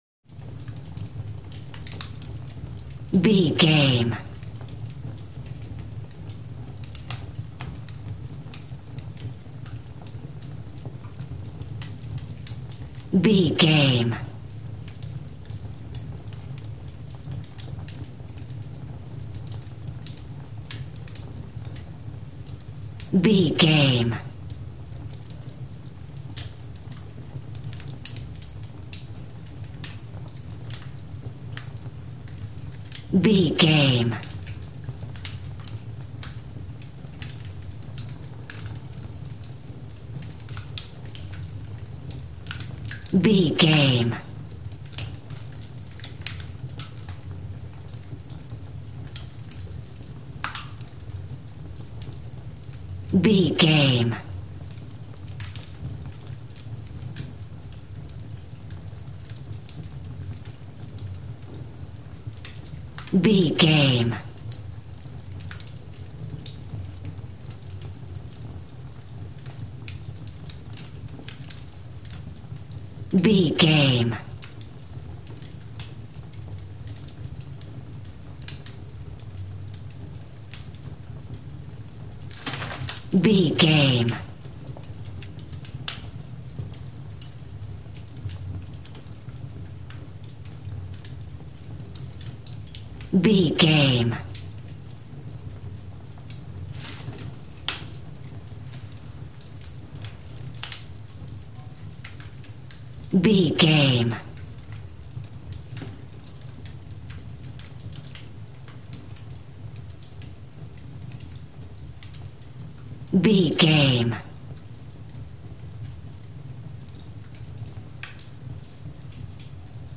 Fire with crakle burning house int
Sound Effects
torch
fireplace